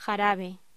Locución: Jarabe